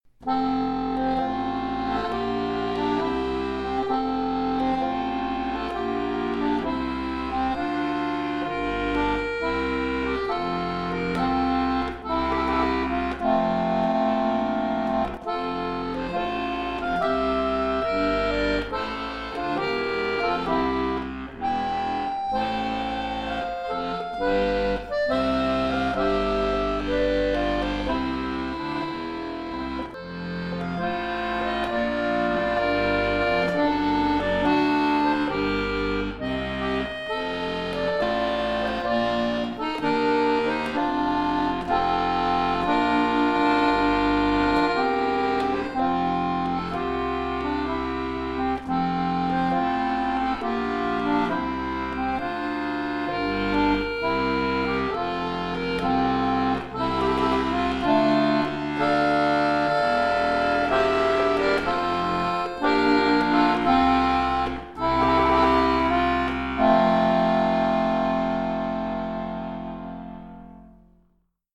Sehr gefühlvolle
Arrangement für Akkordeon solo
Klassisch